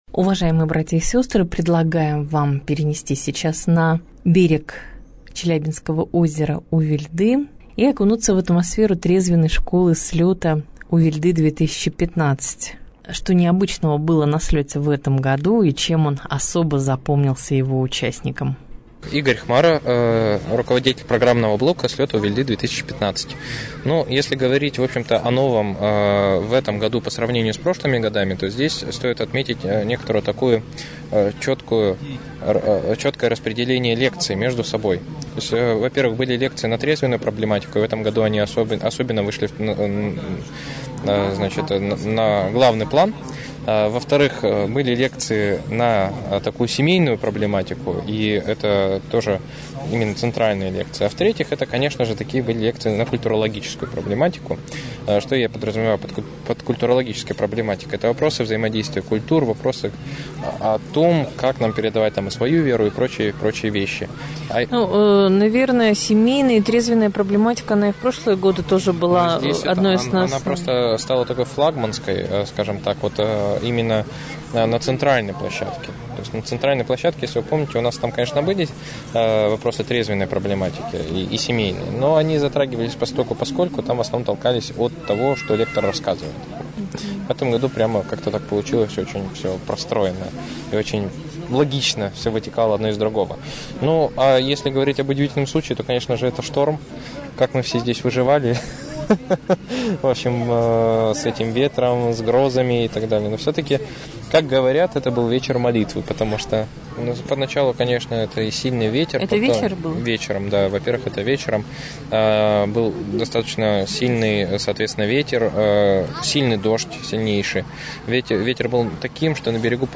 Репортаж дня